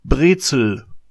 PRET-səl; from German: Breze(l) or Bretzel, pronounced [ˈbʁeːtsl̩]